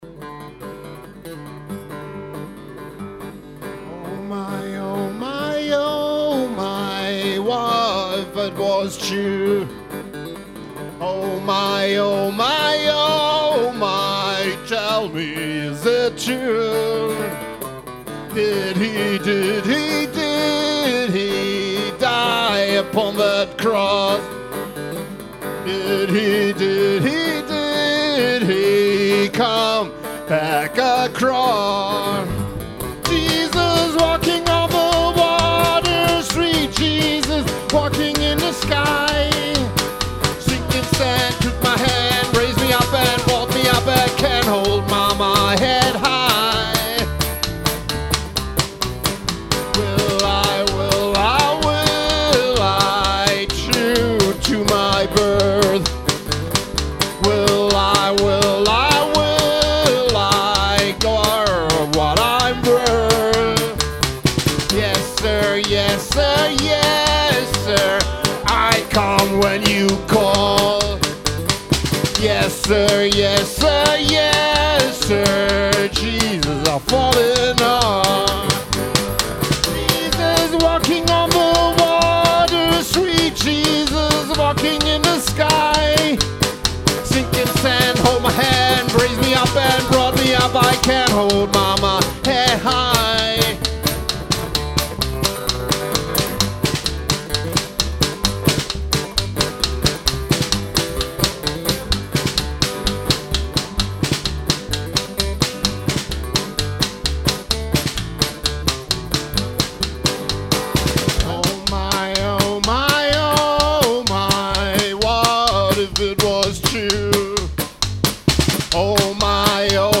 Genre: Folk.